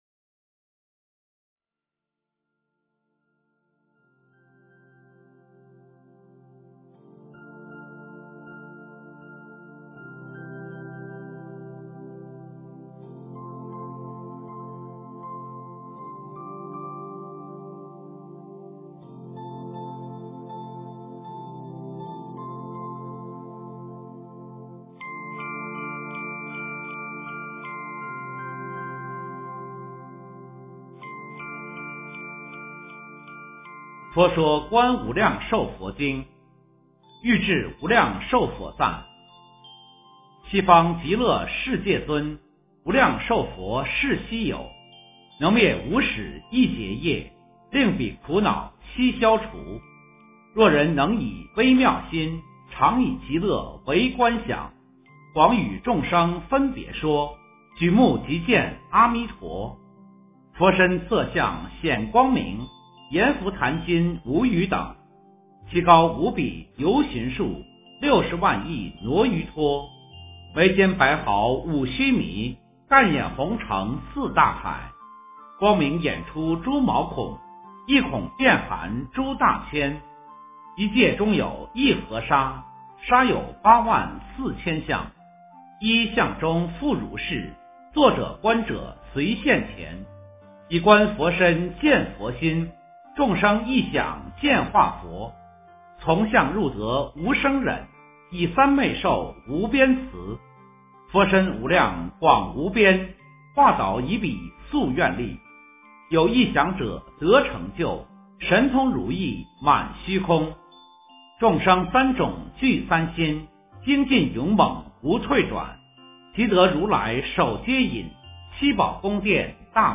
诵经
佛音 诵经 佛教音乐 返回列表 上一篇： 大般若波罗蜜多经第494卷 下一篇： 金刚经 相关文章 六世达赖喇嘛情歌--推荐 六世达赖喇嘛情歌--推荐...